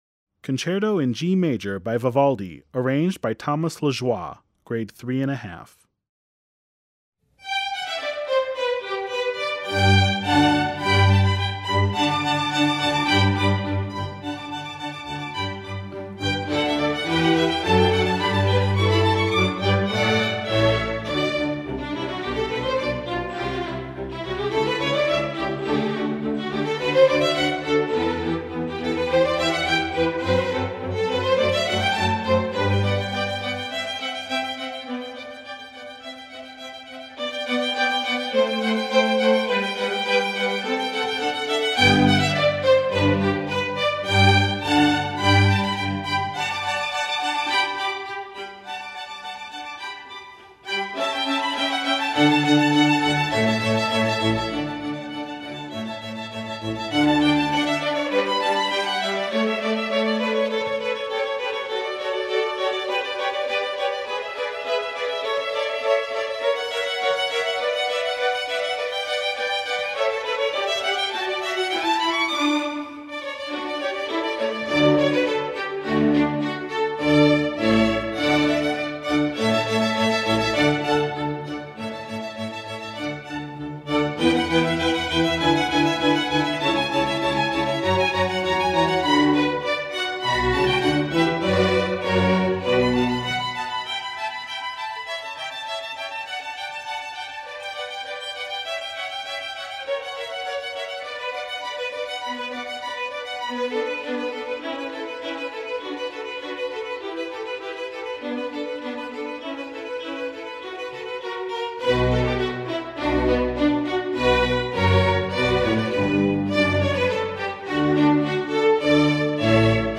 String Orchestra (MD)   Score